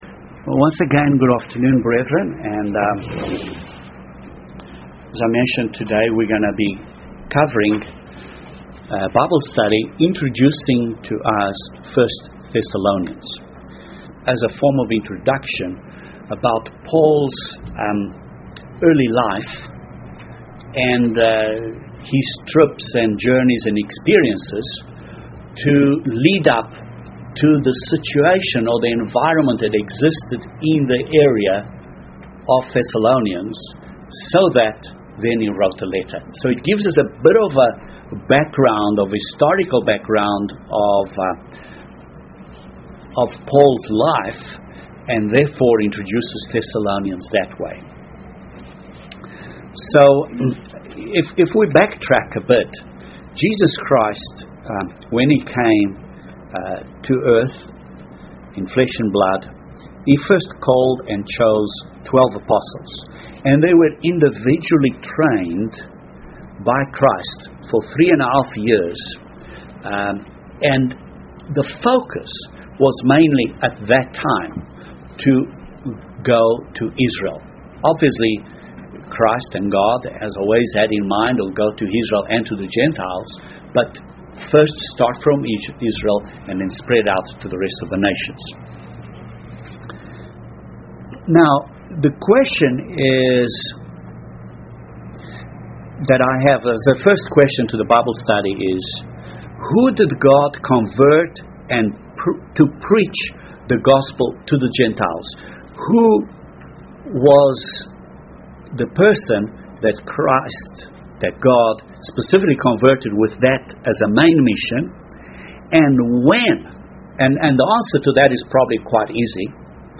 This Bible study discusses Paul's early life and the Church's conditions during the apostolic age to set the scene of the then prevalent situation as an introduction to future studies on Thessalonians.